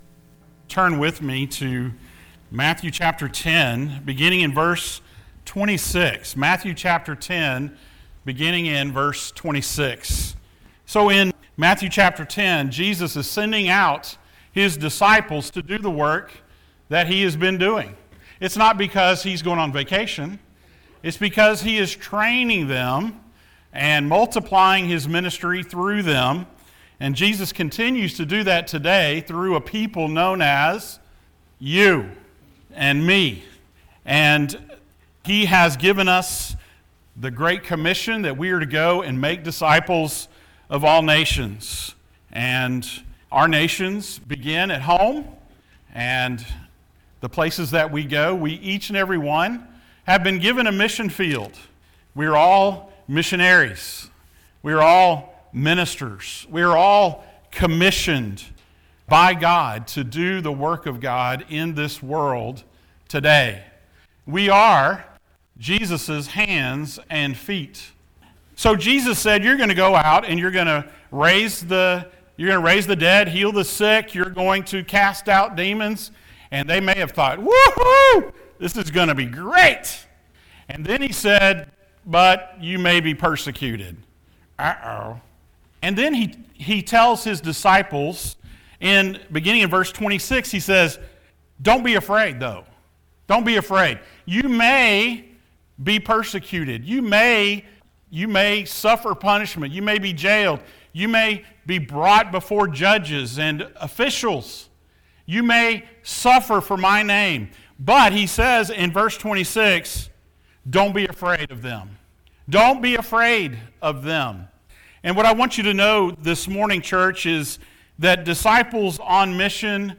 Passage: Matthew 10:26-33, Acts 5:27-29 Service Type: Sunday Morning